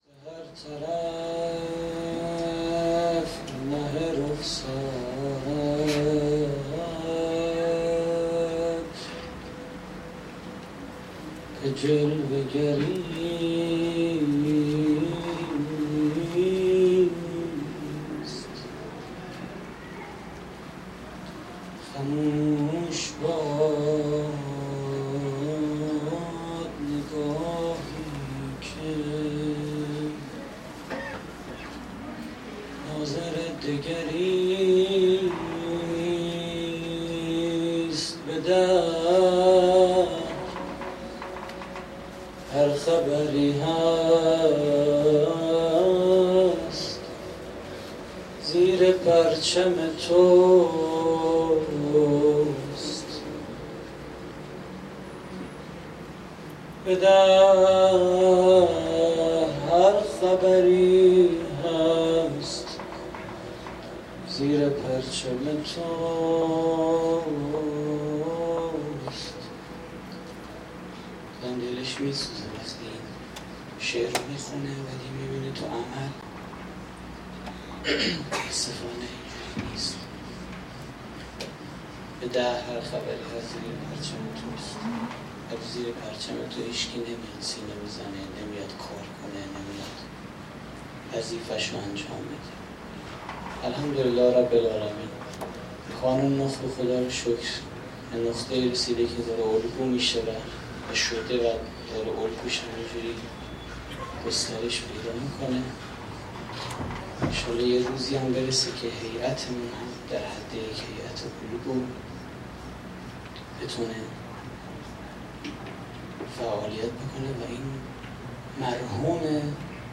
سخنرانی: مدال نوکری اهل بیت (ع)
مراسم عزاداری شهادت امام باقر (ع) / هیئت کریم آل طاها (ع)؛ نازی آباد